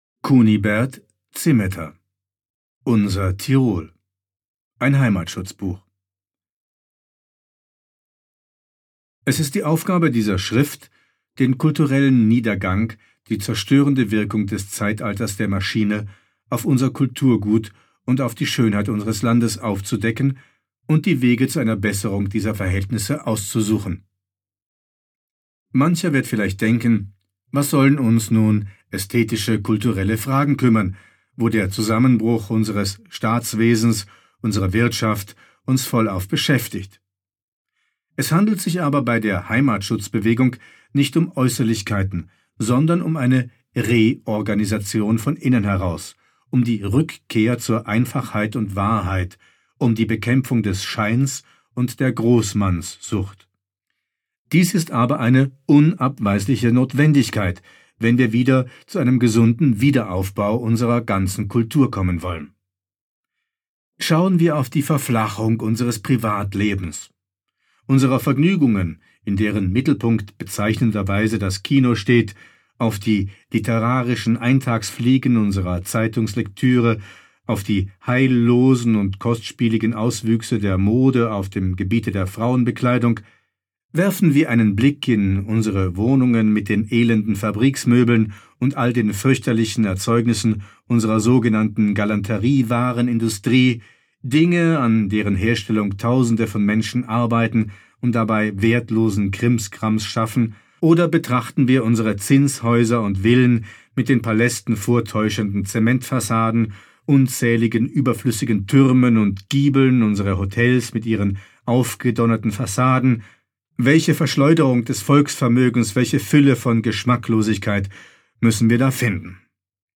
Auszüge aus der Publikation "reprint. ein lesebuch zu architektur und tirol"